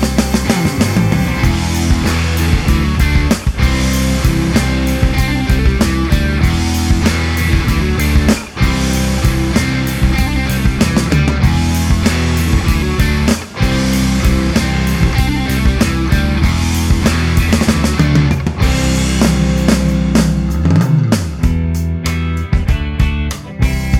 no Backing Vocals Indie / Alternative 3:29 Buy £1.50